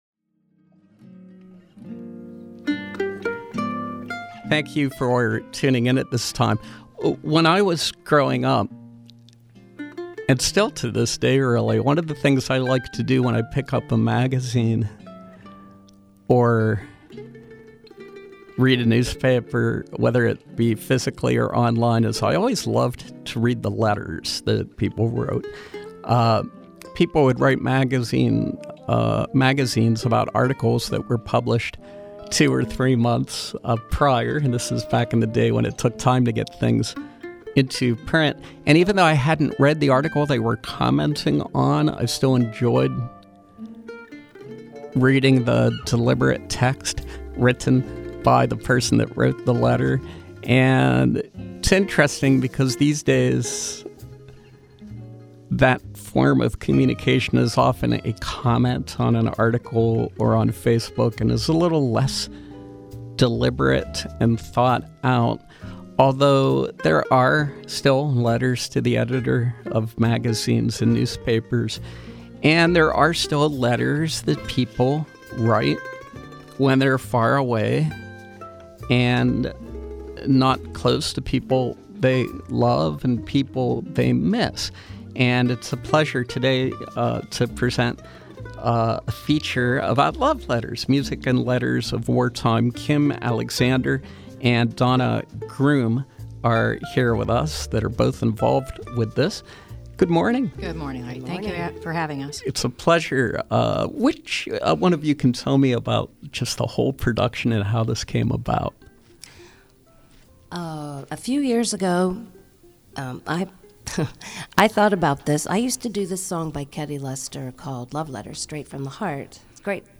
In Studio Pop-Up
Interviews , Performance